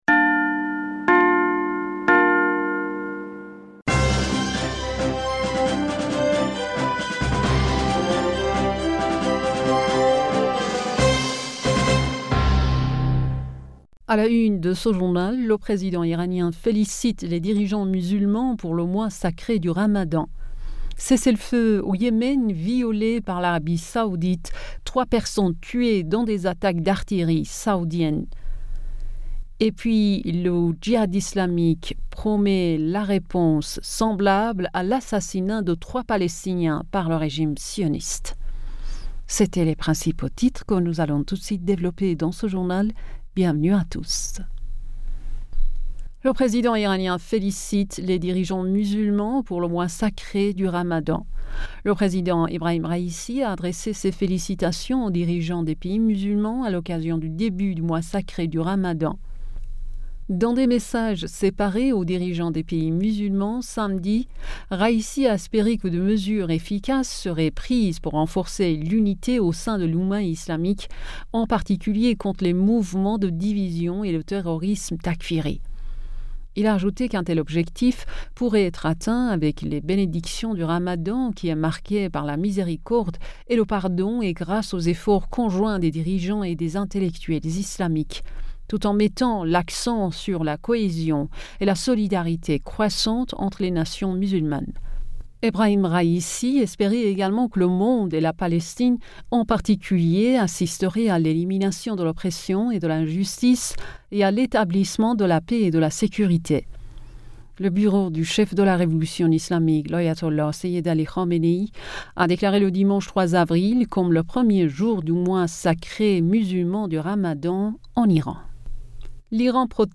Bulletin d'information Du 03 Avril 2022